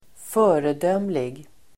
Ladda ner uttalet
Uttal: [²f'ö:redöm:lig]